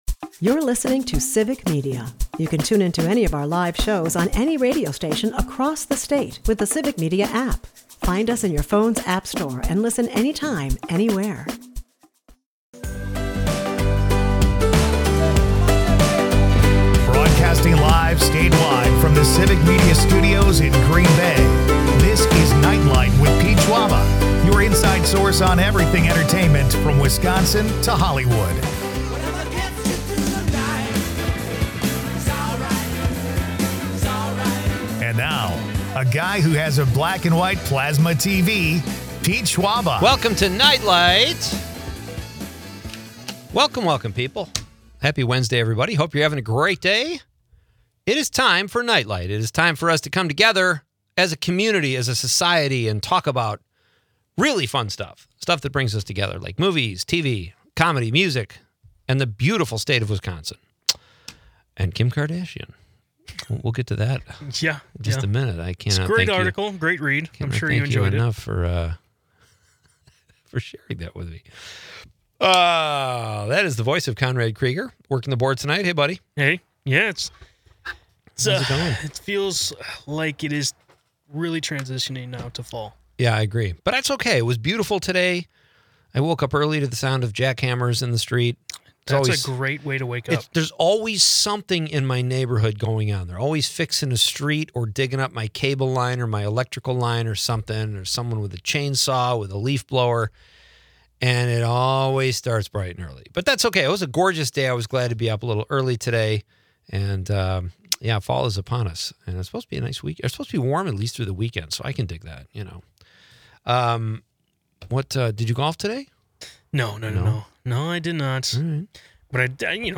The episode also celebrates National Grouch Day with listeners chiming in on their favorite grouches, from the classic Oscar the Grouch to sitcom legends like Red Fox.